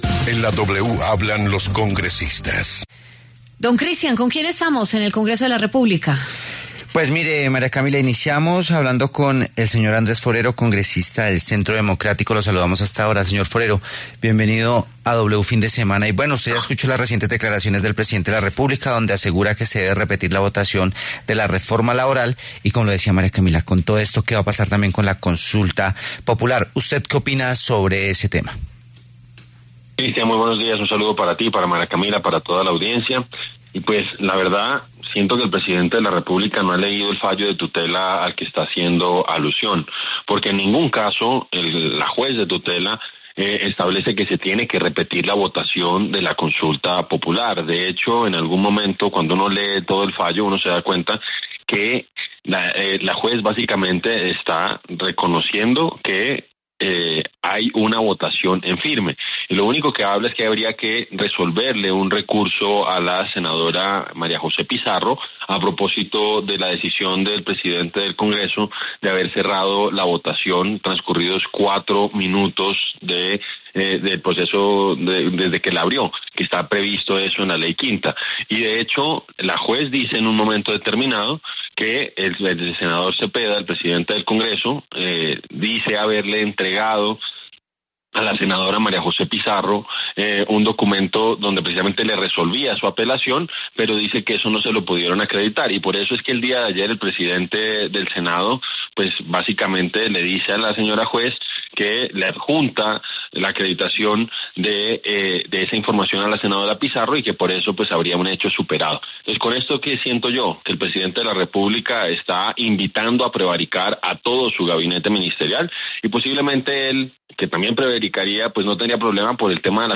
El representante del Centro Democrático, Andrés Forero, aseguró que la juez nunca se refirió a que se deba repetir la votación de la consulta popular.